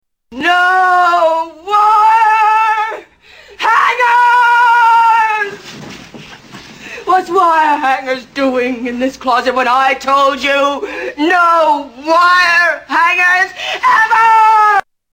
Tags: Greatest Movie Monologues Best Movie Monologues Movie Monologues Monologues Movie Monologue